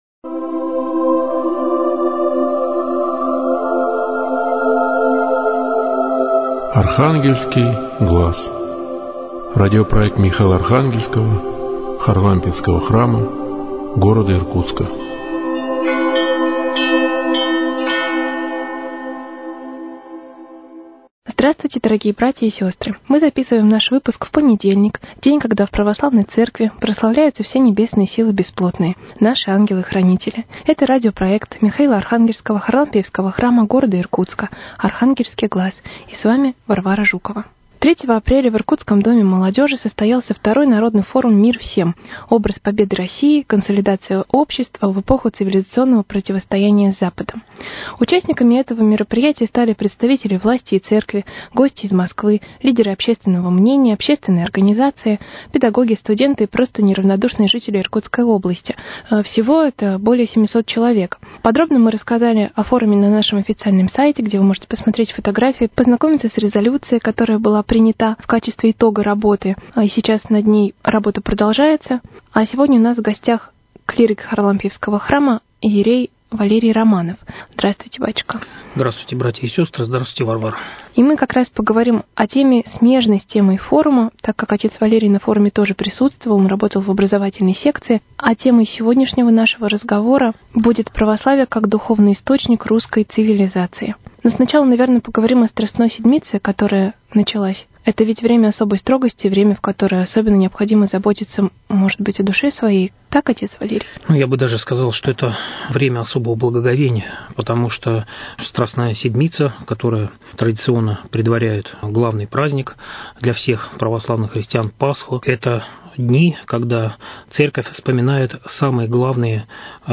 Очередная передача из цикла Михаило – Архангельского Харлампиевского храма.